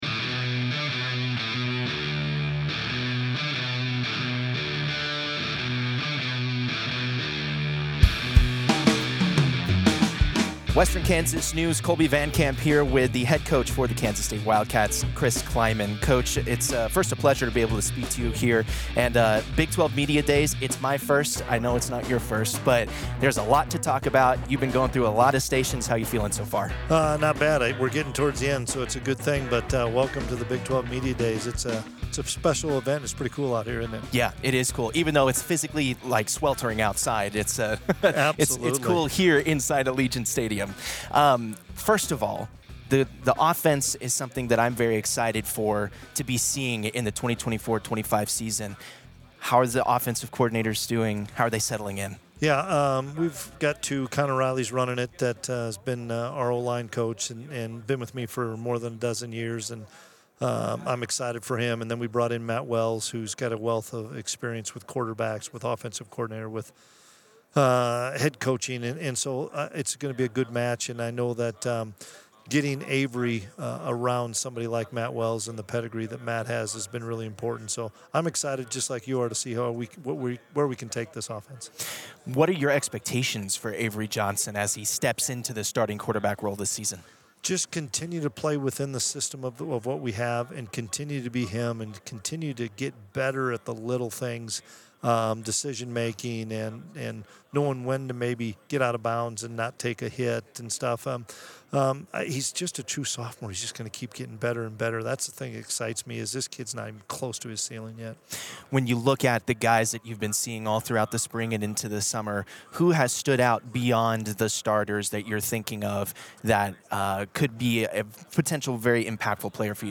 Las Vegas, NV – Western Kansas News sports staff spent this week at the Big 12 Media Days covering the Kansas State Wildcats and the Kansas Jayhawks, as well as acquiring exclusive interviews with other programs and members of the media.